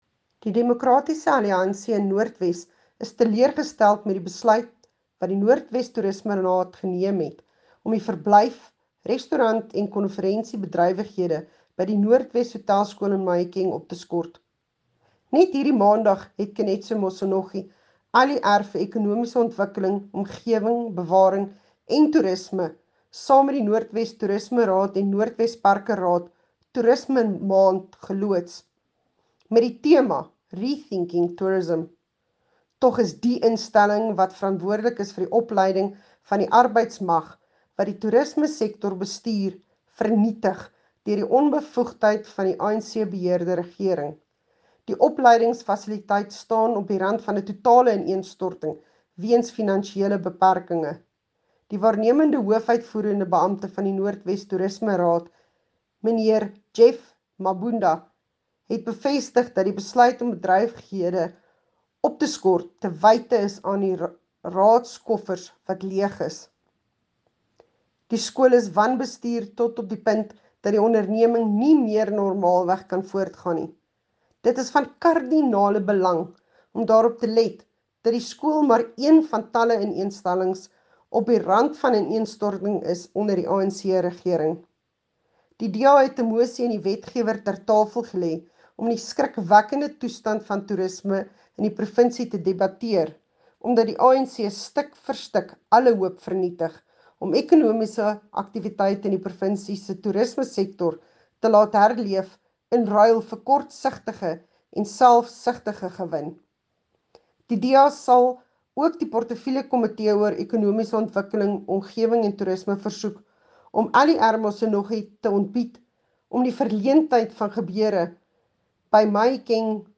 Note to Editors: Please find the attached soundbite in
Afrikaans by Jacqueline Theologo MPL.